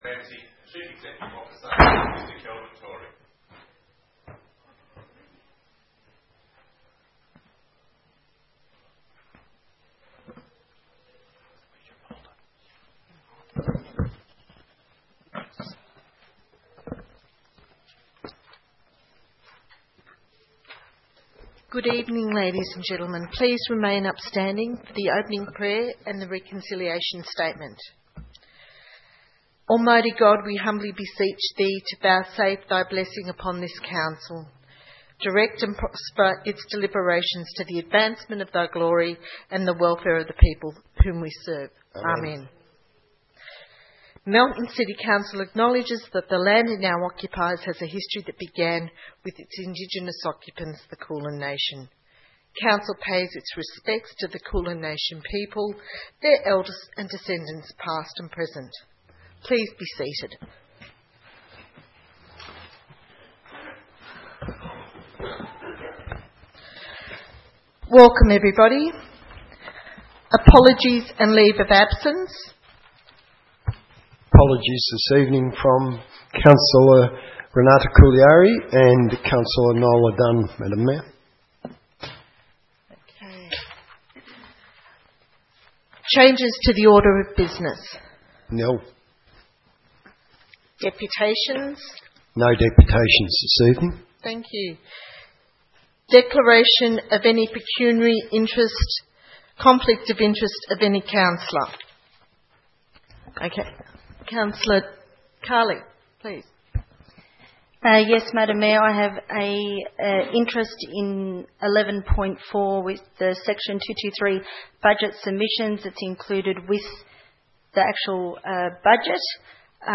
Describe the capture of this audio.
23 June 2015 - Ordinary Council Meeting